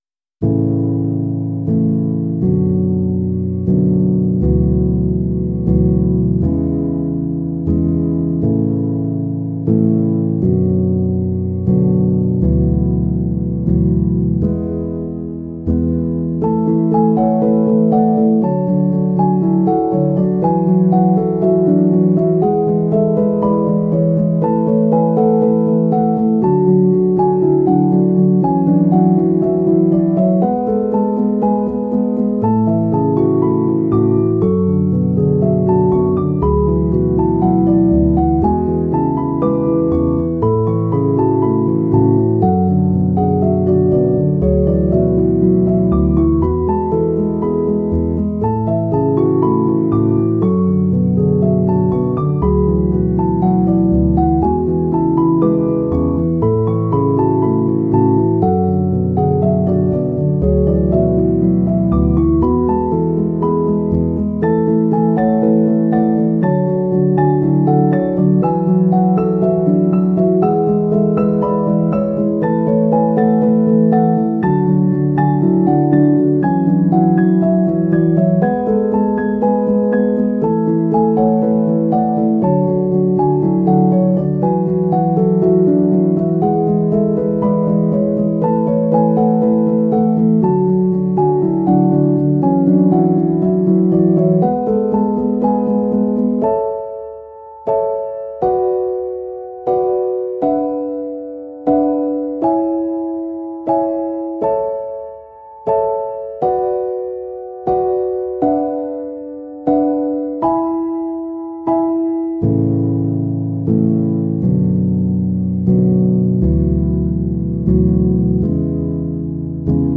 PIANO Q-S (31)